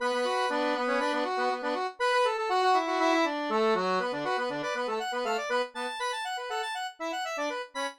月光下的吨位 手风琴层
Tag: 120 bpm Fusion Loops Accordion Loops 1.35 MB wav Key : Unknown